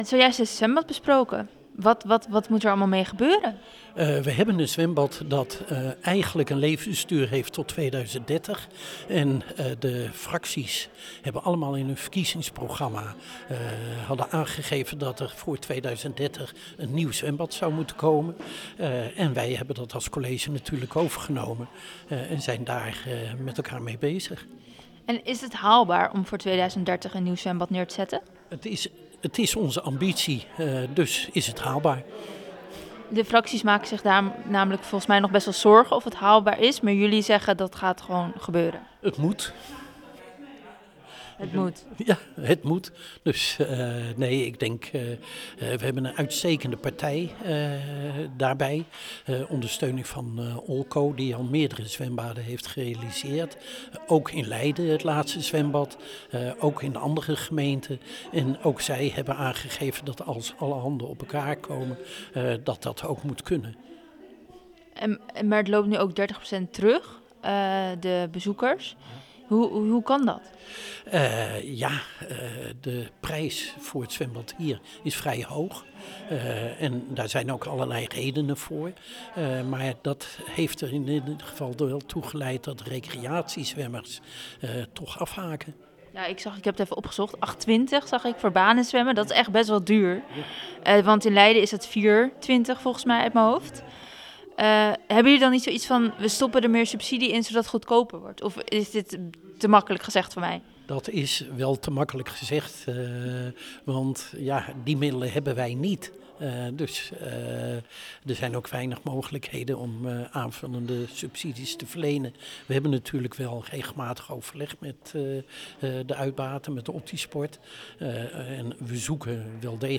Wethouder Tim van Tongeren in gesprek